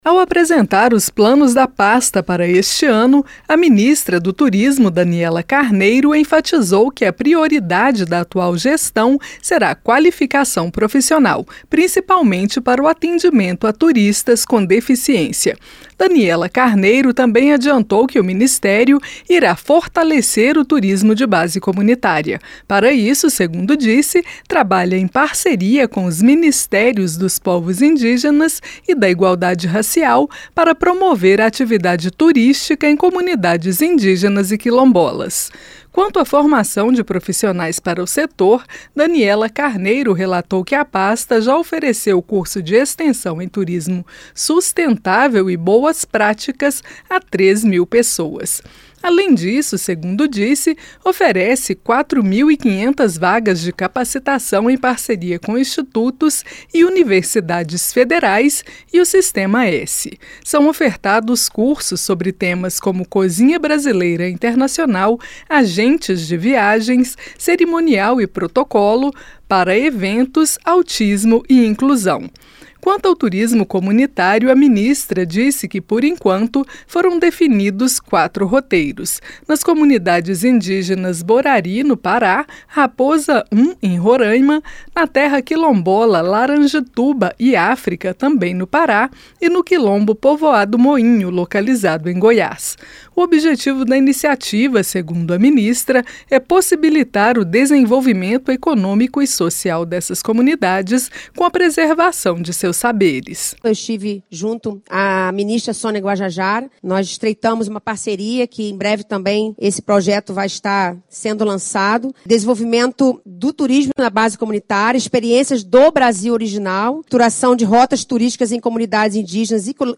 Bibo Nunes e Daniela Carneiro, durante audiência pública da Comissão de Turismo